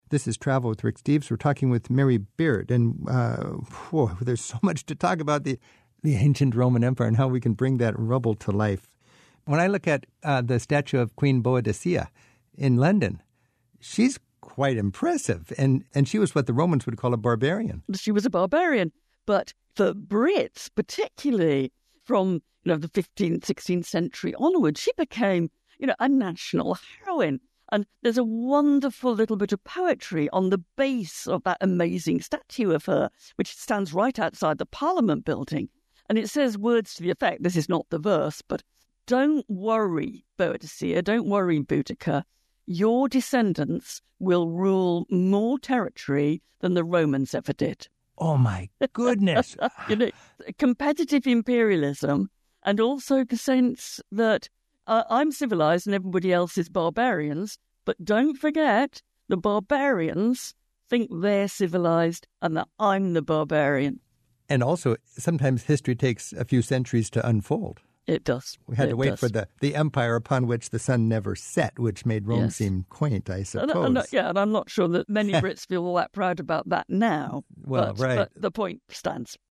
More with Mary Beard - Mary Beard tells Rick what the statue of Boudicca (aka Boadicea) in front of Parliament in London represents, in depicting a British response to the Roman invaders.